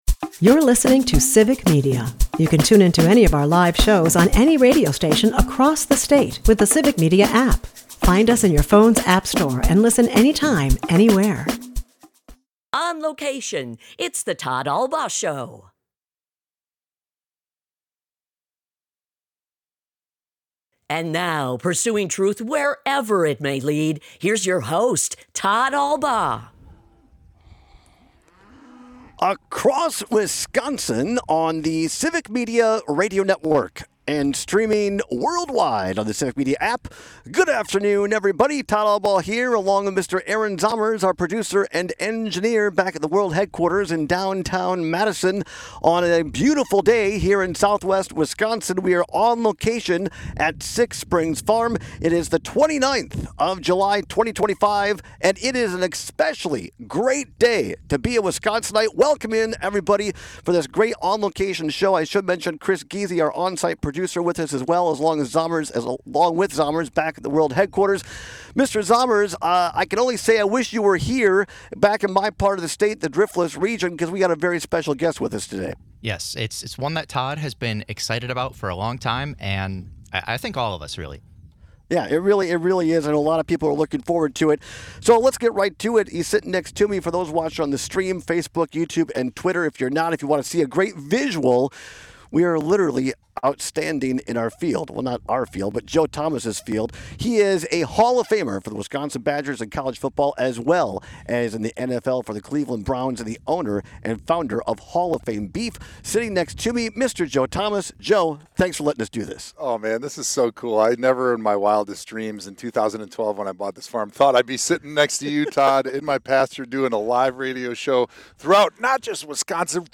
broadcasts live from Six Springs Farm in Southwest Wisconsin! Six Springs Farm is owned and operated by NFL Hall-of-Famer Joe Thomas, who joins us today to talk about his journey.